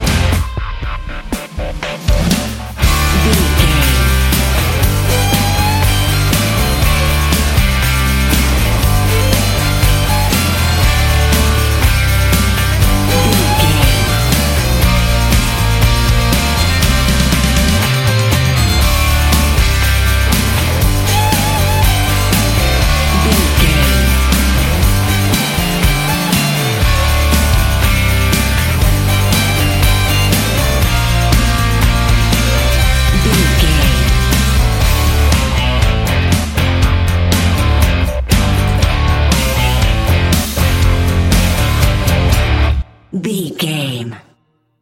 Aeolian/Minor
drums
electric guitar
bass guitar
violin
country rock